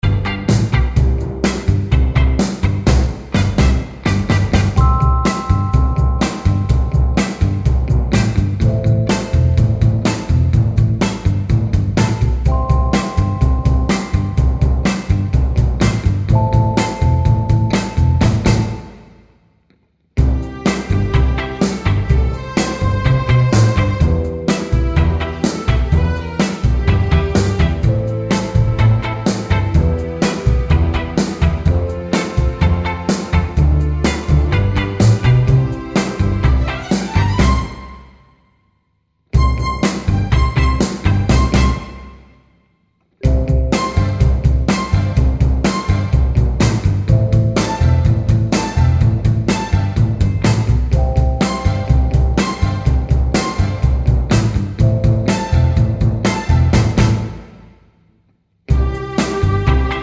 no Backing Vocals Oldies (Female) 2:34 Buy £1.50